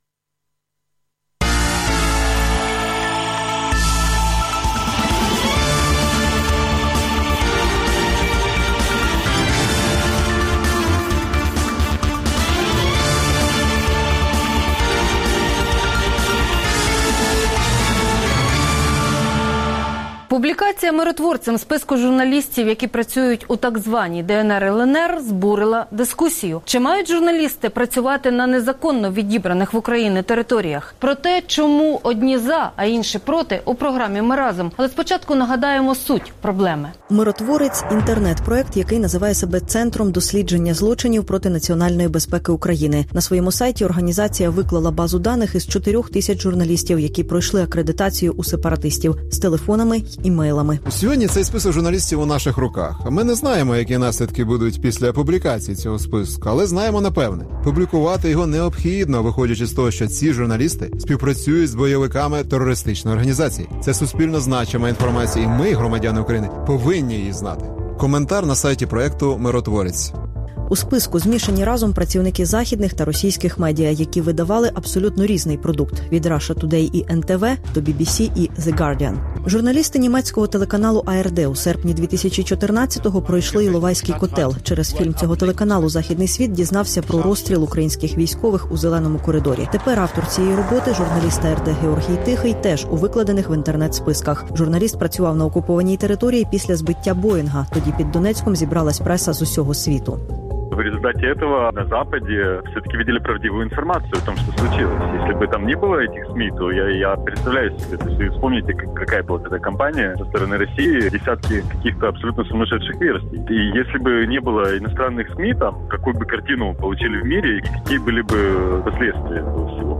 Гість студії – Тетяна Попова, заступник міністра інформаційної політики України.